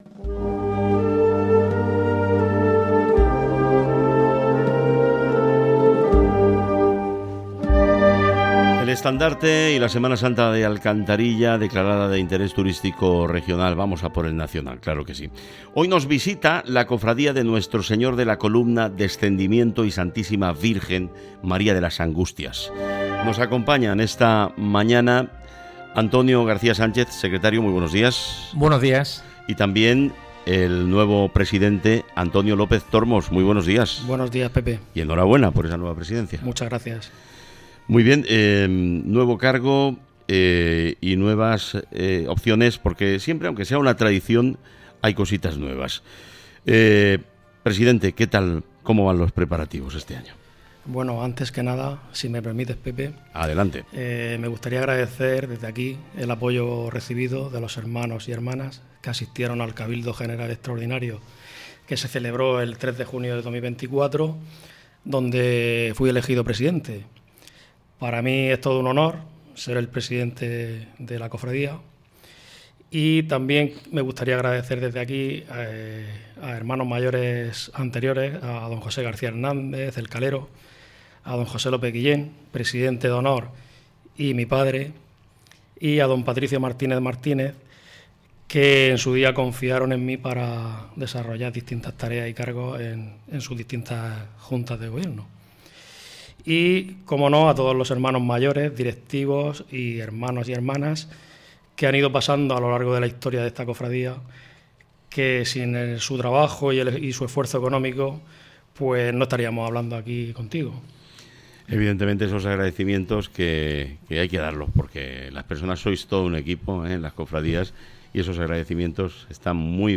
Escucha aquí el programa completo.